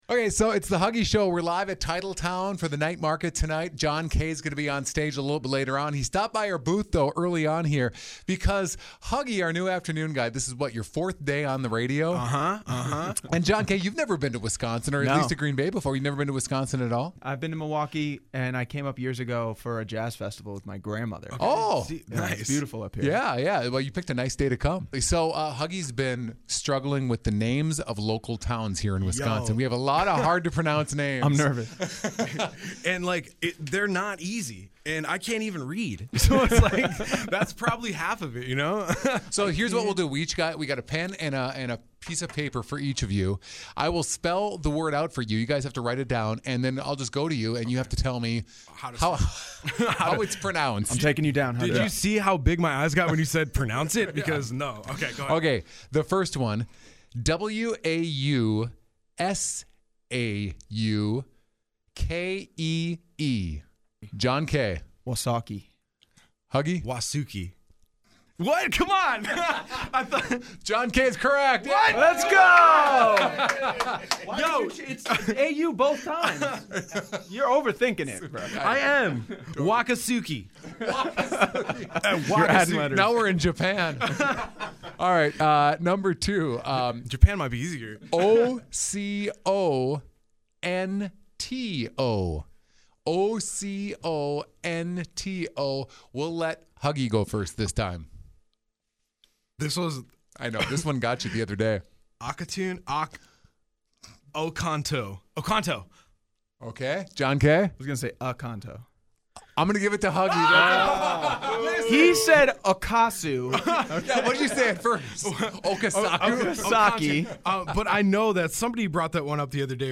Anyway take a listen to this game we played live on WIXX…